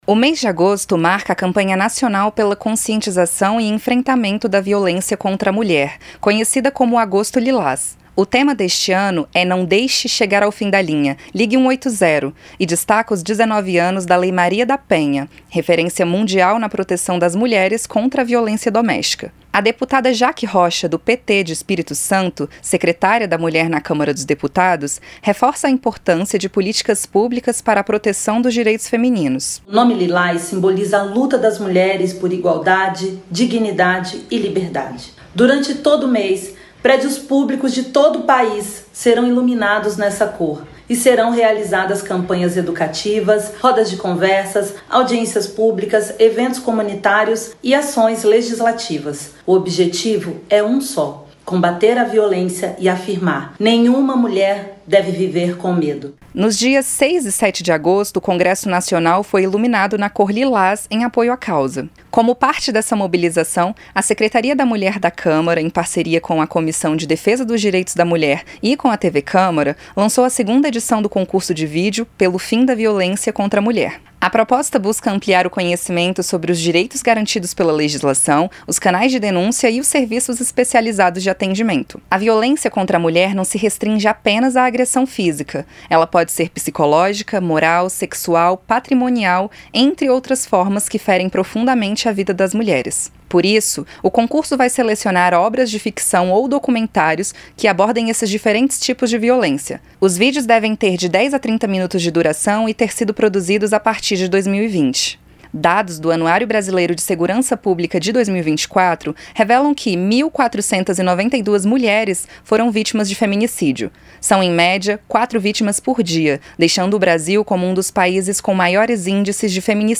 PARA MARCAR O AGOSTO LILÁS, CÂMARA DOS DEPUTADOS LANÇA CONCURSO DE VÍDEO PELO FIM DA VIOLÊNCIA CONTRA A MULHER. A REPORTAGEM